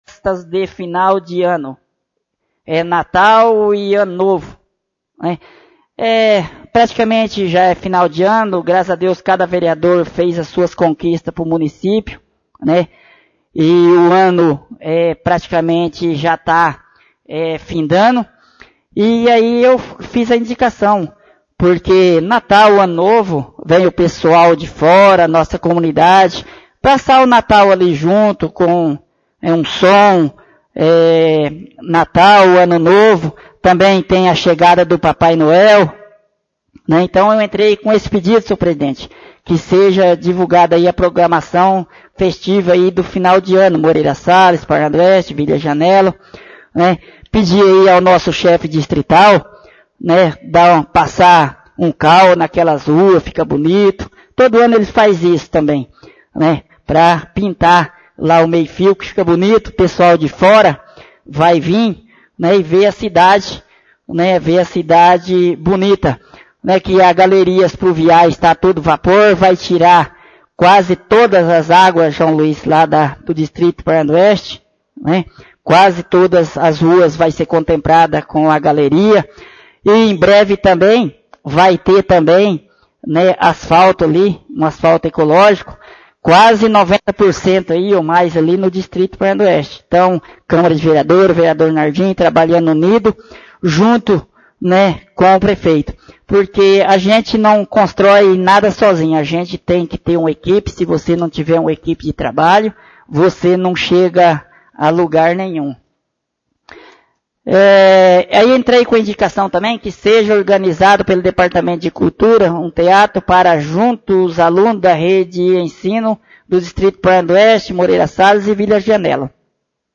33° Sessão Ordinária